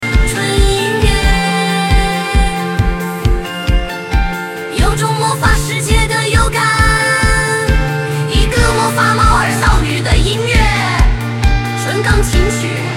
纯音乐，有种魔法世界的幽感，一个魔法猫耳少女的音乐，纯钢琴曲